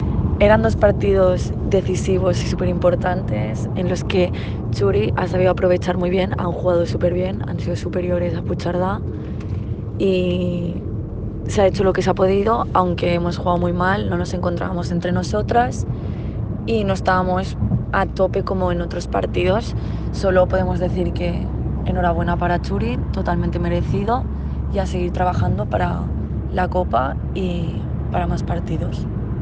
La davantera